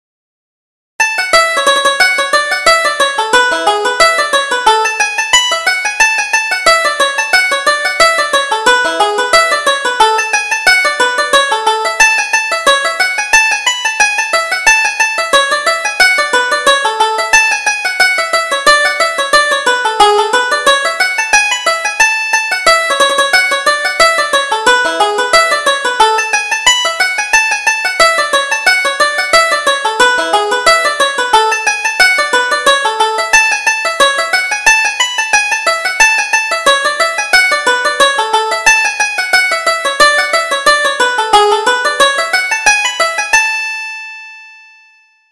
Reel: The Money Musk